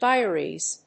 /ˈdaɪɝiz(米国英語), ˈdaɪɜ:i:z(英国英語)/